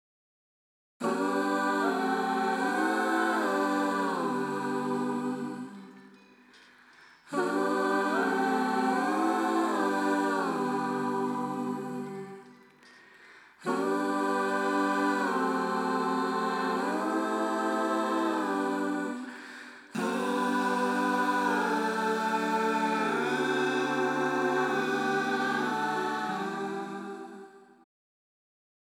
Processing of backing vocals.
Left section of AVE adds air with a “Dolby-A style” high frequency enhancement. Right section of AVE adds stereo width by slight pitch shifting and delay:
The overall effect is big added depth and expression.